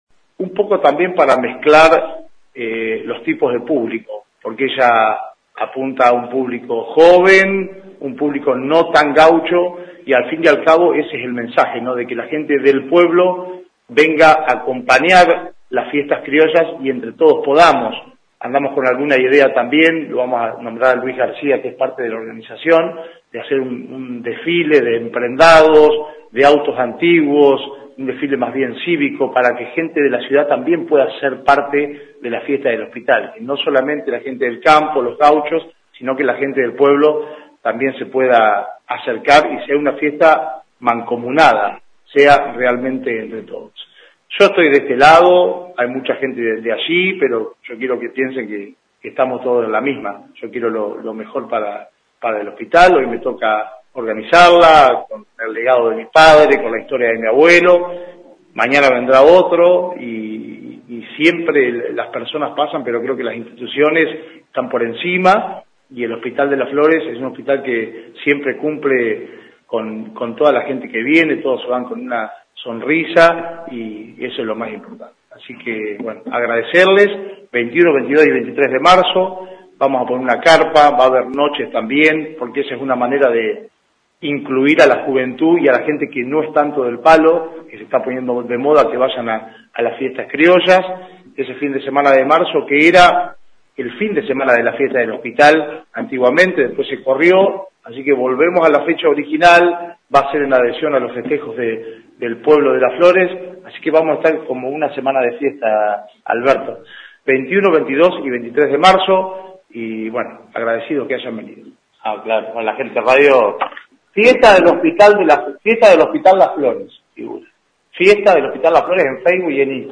En conferencia de prensa que tuvo lugar en la mañana de este jueves en el SUM de la Asociación Cooperadora fue presentada oficialmente la 26ta. edición de la Fiesta del Hospital de Las Flores que se llevará a cabo los días 21, 22 y 23 de marzo en el campo de destrezas criollas del Centro Tradicionalista «La Tacuara».
Conferencia-parte-tres.mp3